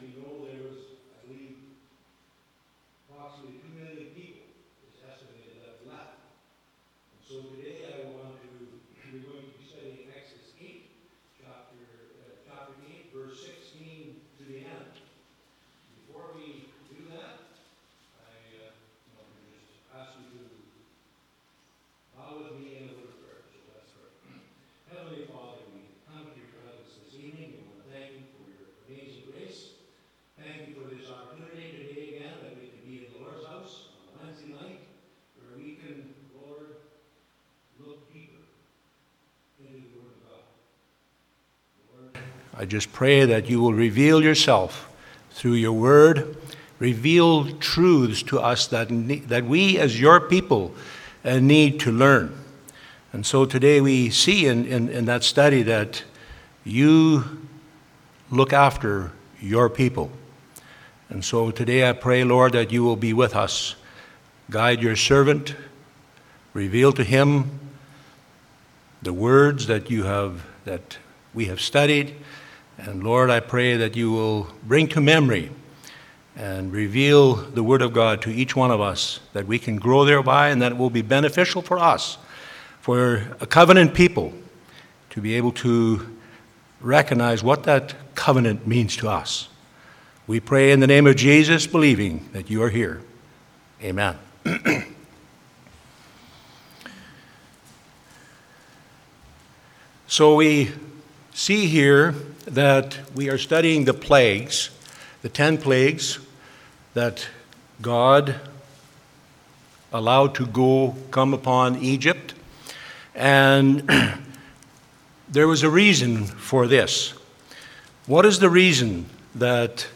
Church Bible Study – The Ten Plagues of Egypt